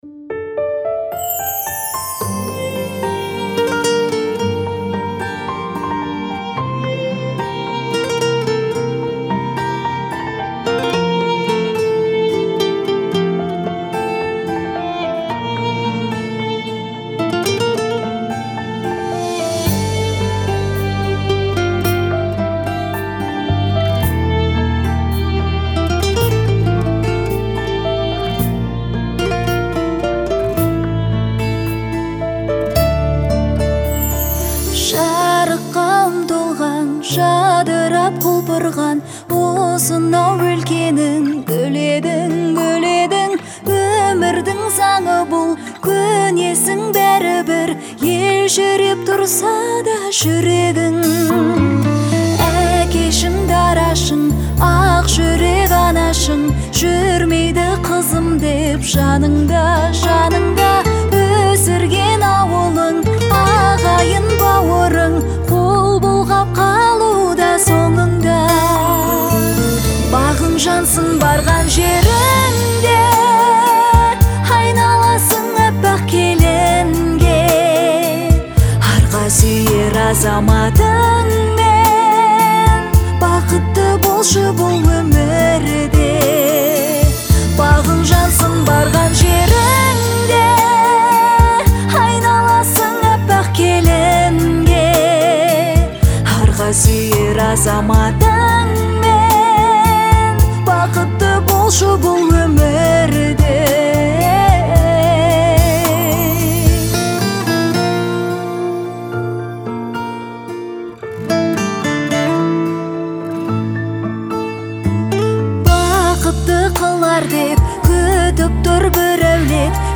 это трогательная песня в жанре казахского фольклора